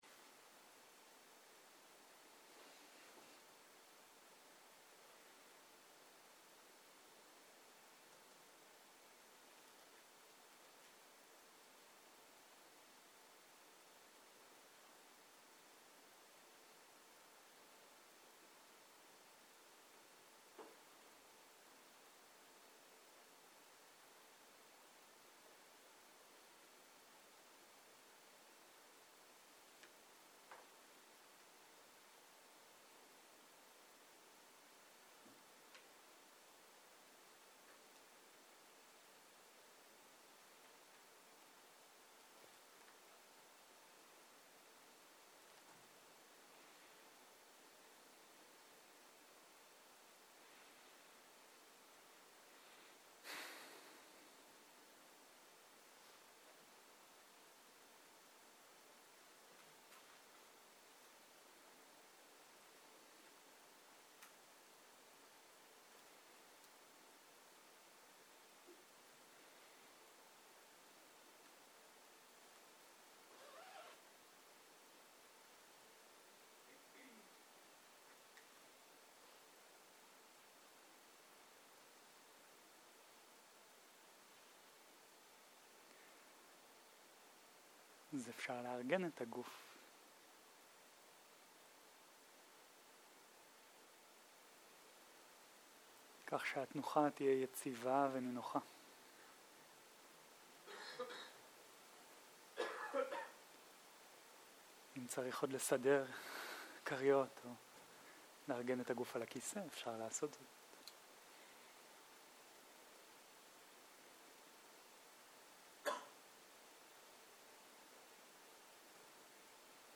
מדיטציה מונחית - נשימה בגוף
סוג ההקלטה: מדיטציה מונחית שפת ההקלטה